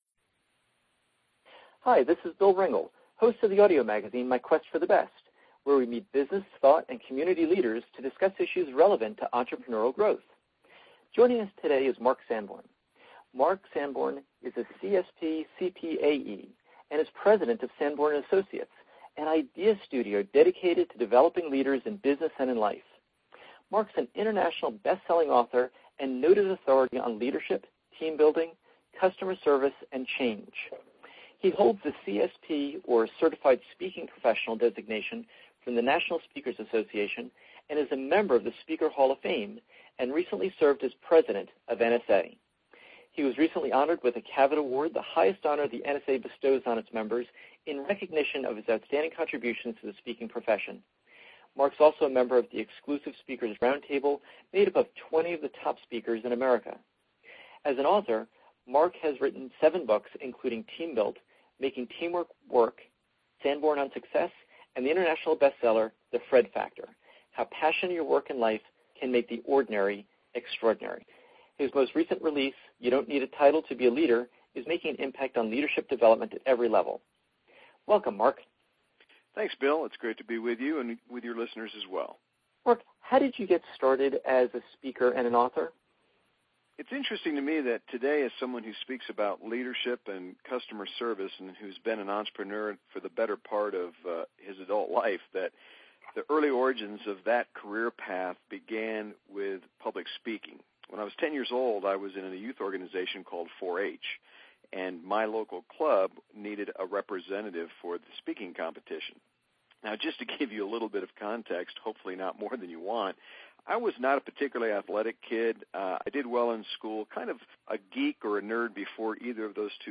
Practical conversations with top business authors to help small business leaders grow faster, lead better, and apply smarter ideas to sales, culture, and strategy.